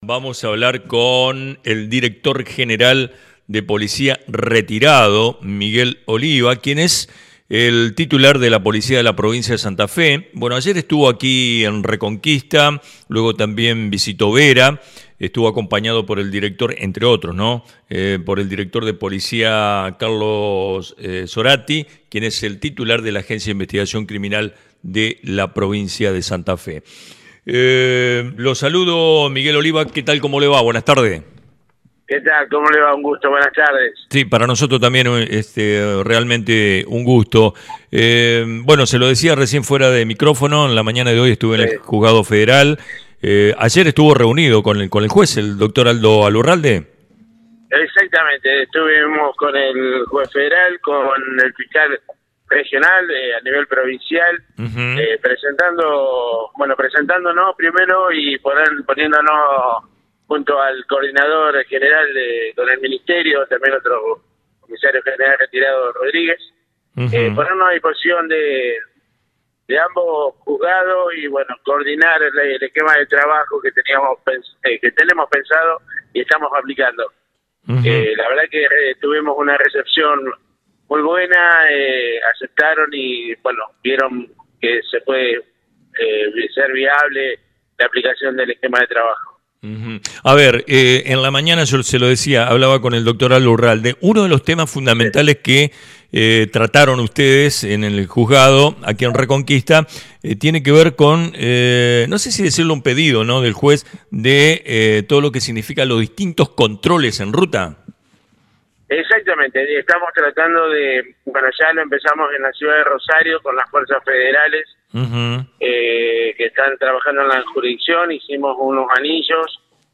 Entrevista-al-jefe-de-Policia-Miguel-Oliva-online-audio-converter.com_.ogg